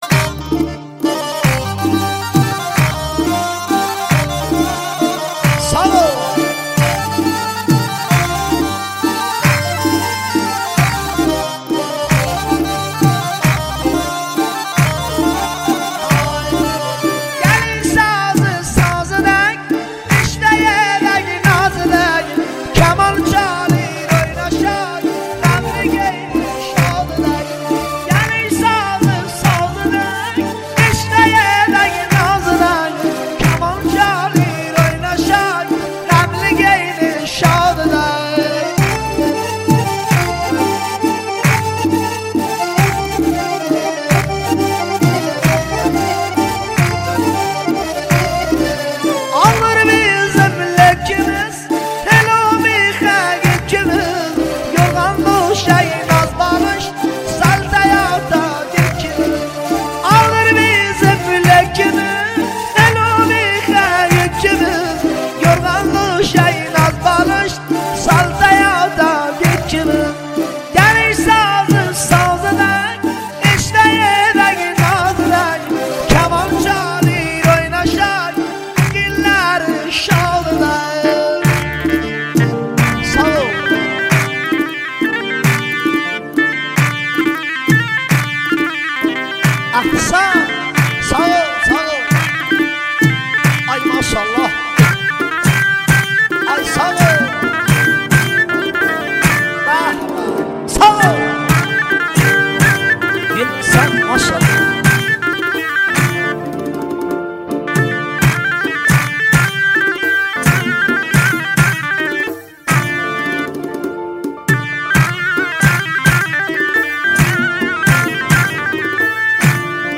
آهنگ ترکی